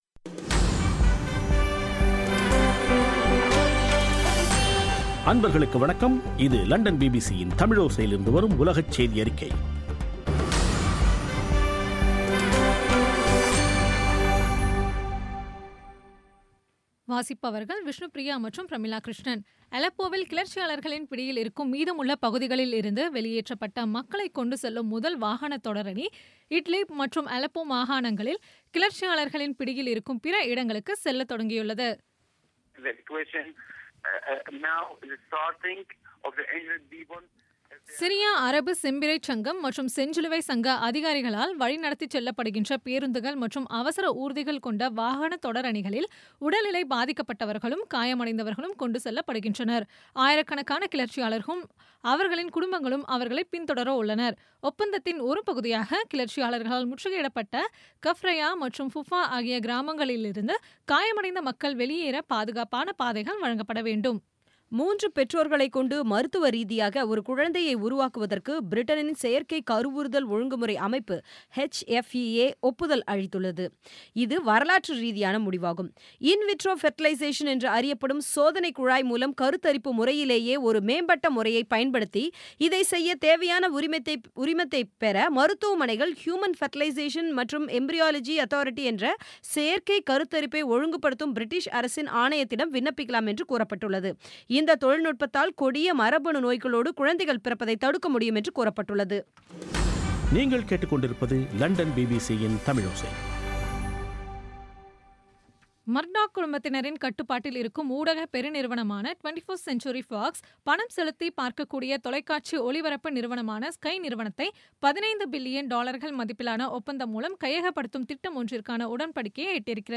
பிபிசி தமிழோசை செய்தியறிக்கை (15/12/2016)